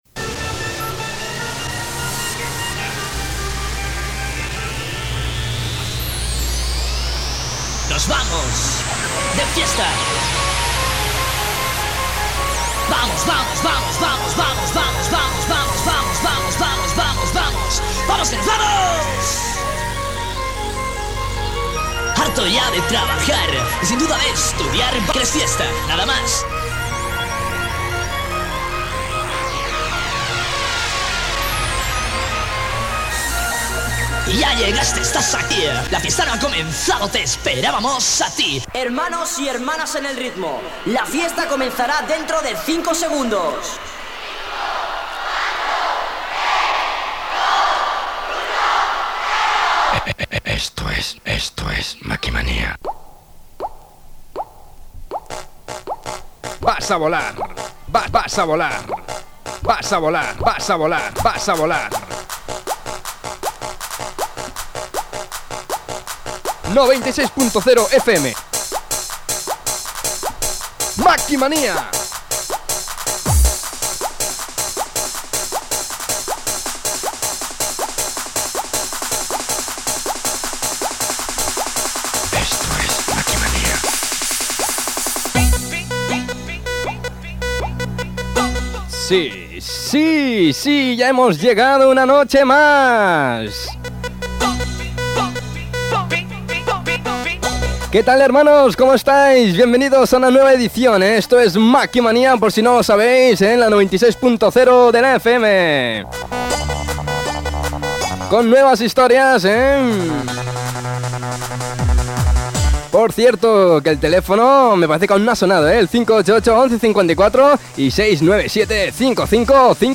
7074ebaf4b60e3d151dd9bc81c4bc745211ba787.mp3 Títol Ràdio Rubí Emissora Ràdio Rubí Titularitat Pública municipal Nom programa Makimanía Descripció Introducció, presentació d'un tema musical i trucades telefòniques dels oients. Gènere radiofònic Musical